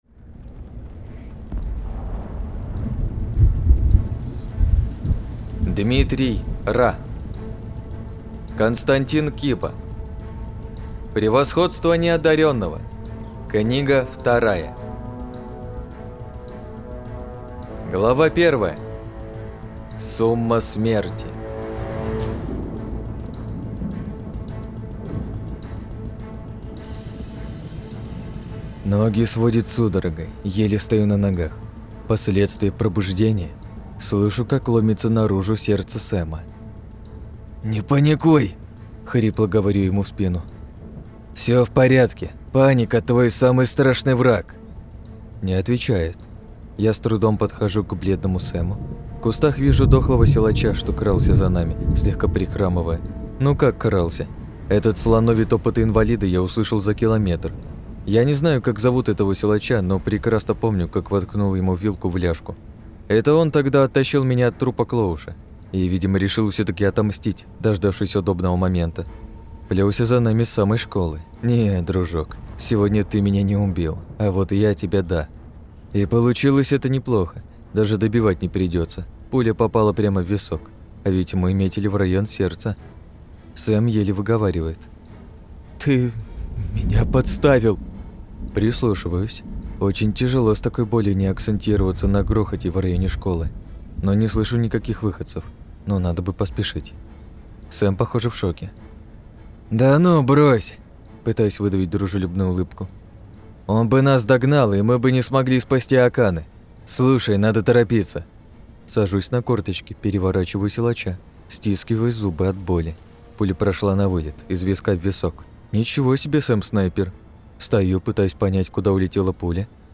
Аудиокнига - слушать онлайн